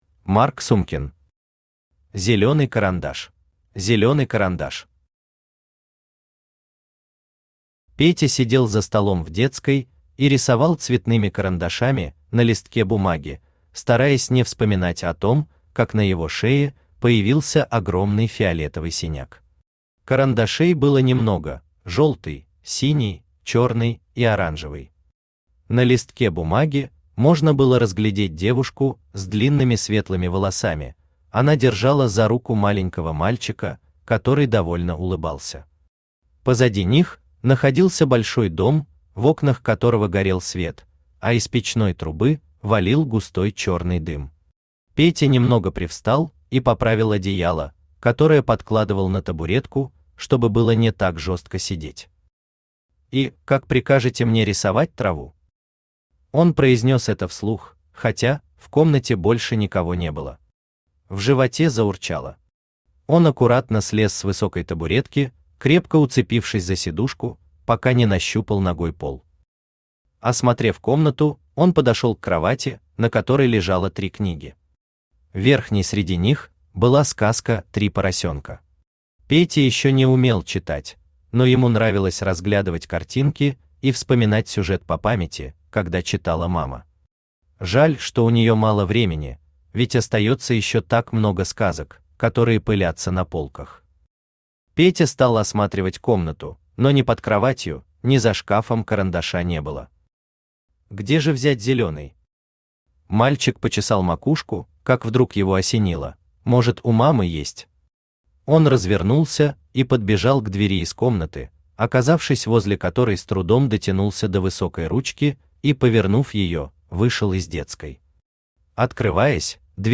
Aудиокнига Зелёный карандаш Автор Марк Сумкин Читает аудиокнигу Авточтец ЛитРес.